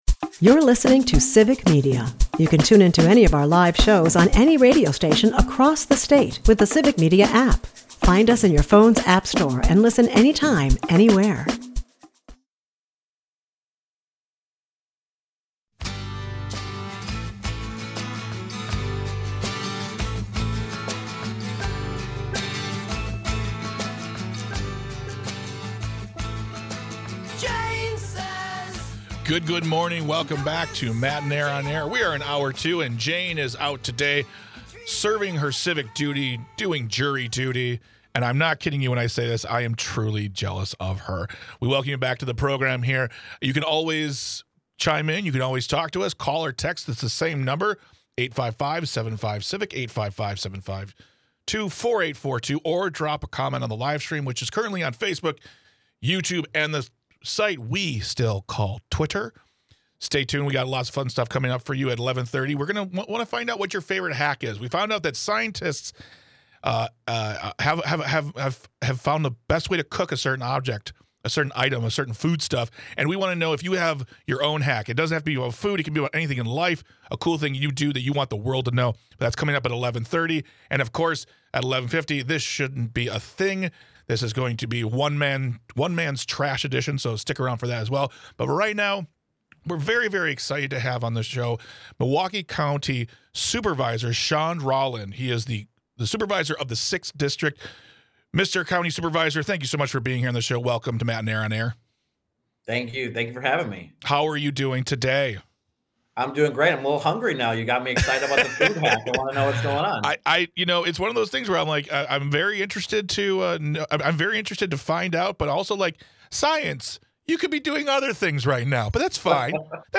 You Should Thinking About Running (Hour 2) February 19, 2025 Guest: Shawn Rolland In the second hour, Milwaukee County Supervisor Shawn Rolland joins the show to talk about his job duties, the push to be the healthiest county in Wisconsin and why running for office isn't an impossible as it may sound.